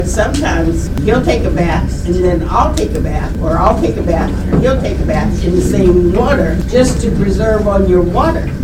At Monday’s press conference of Bel Air residents fighting against high water rates from Maryland Water Service, story after story was shared of high bills and severe water conservation measures.  One resident shared that even basic hygiene is being affected, trying to keep costs down…